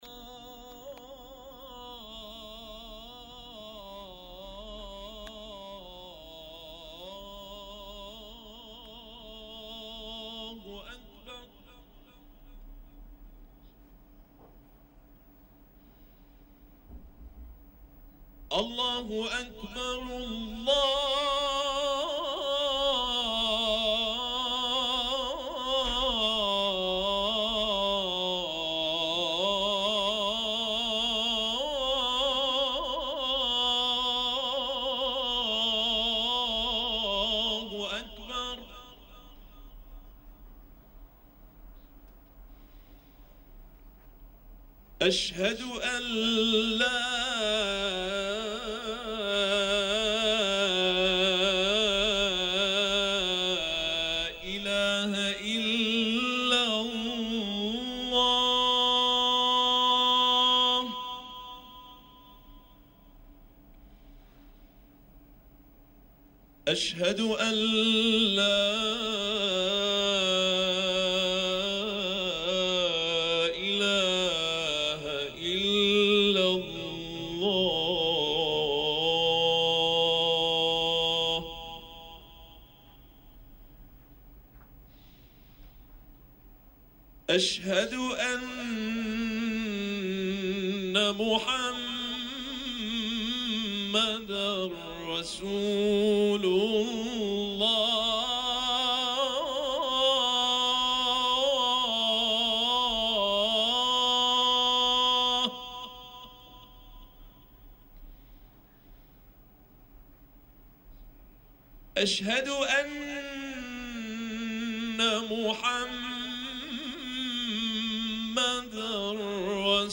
اذان زیبا